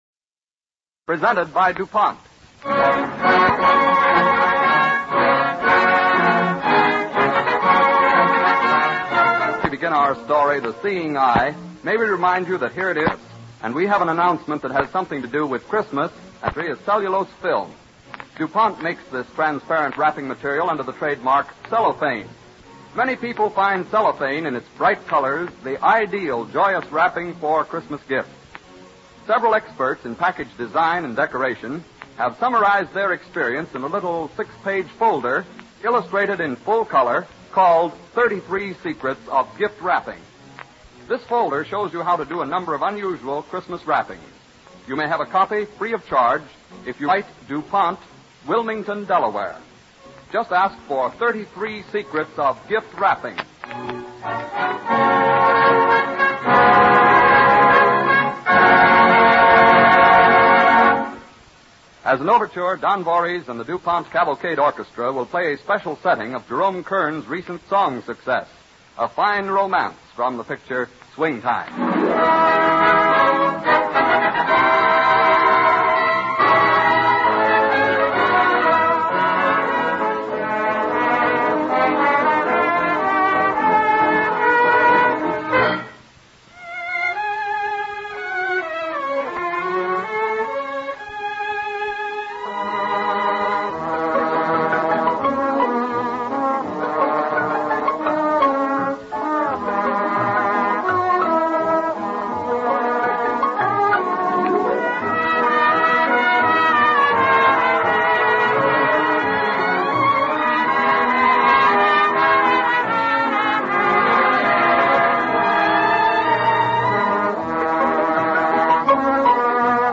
With announcer Dwight Weist